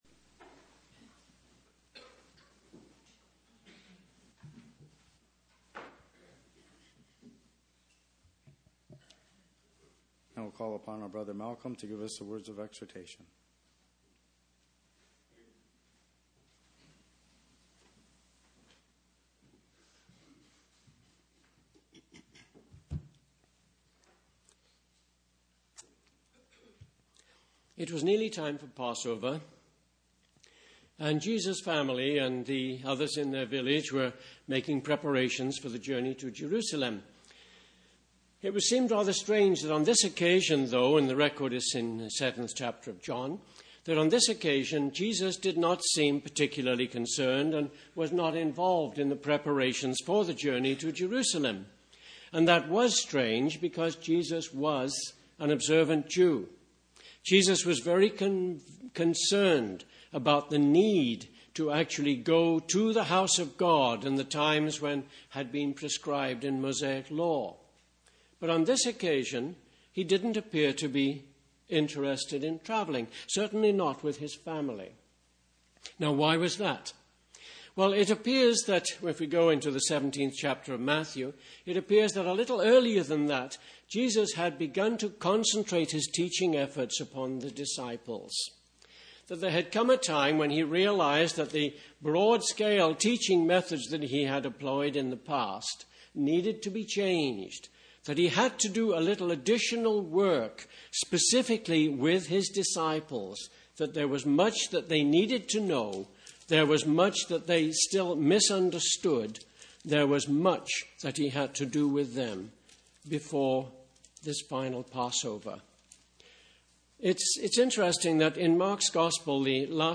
Exhortation 02-16-14